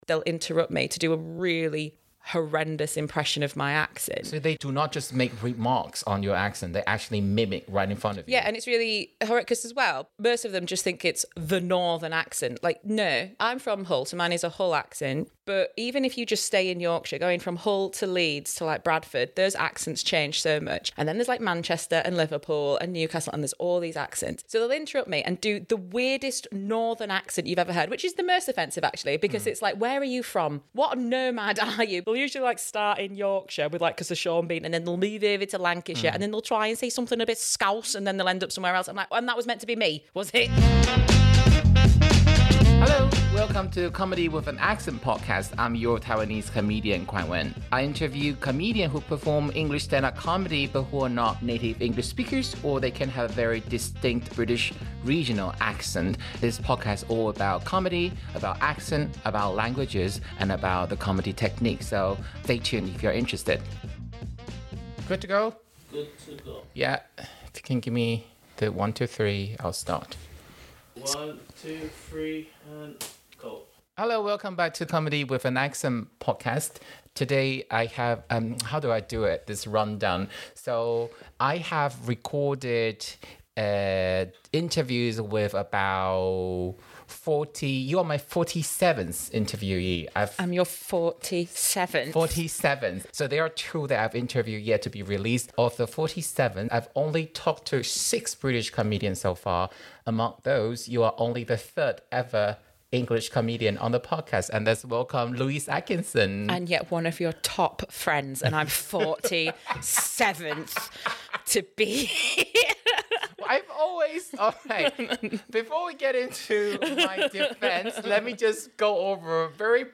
For listeners interested in the numerous accents that exist in the UK, the Hull accent is considered one of the most distinct accents.